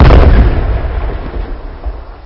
laserBang1.ogg